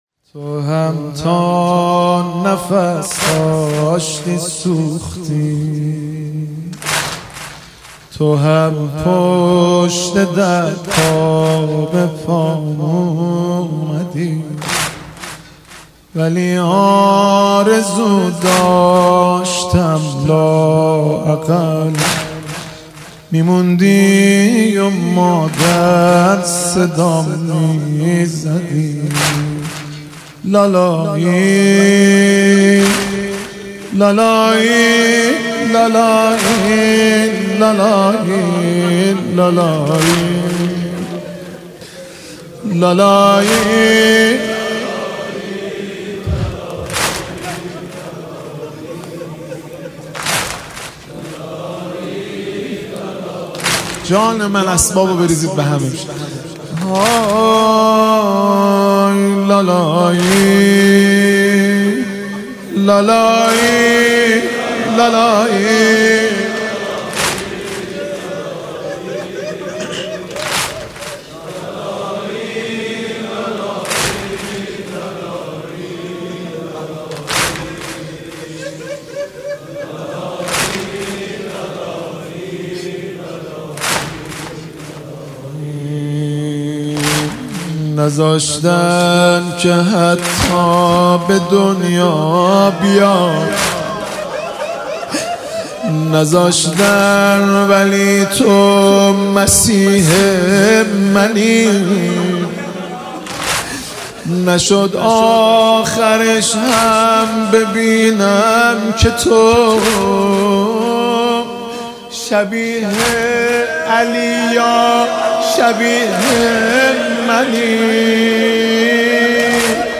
نماهنگ مداحی واحد